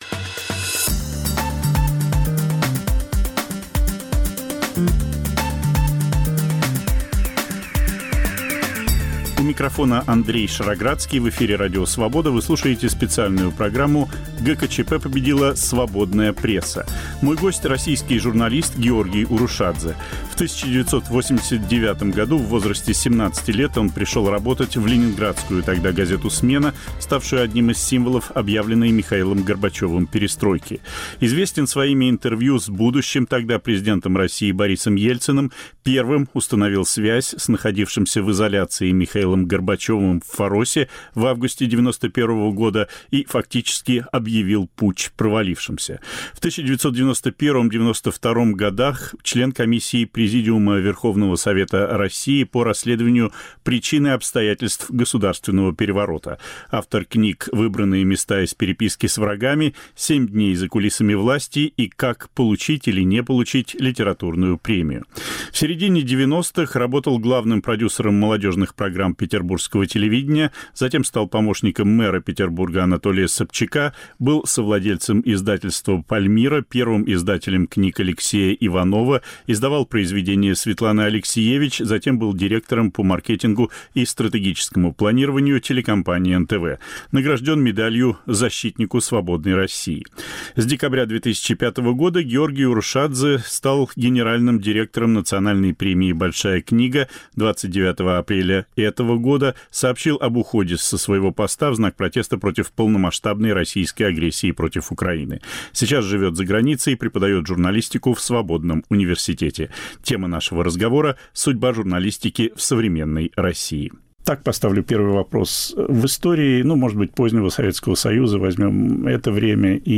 Разговор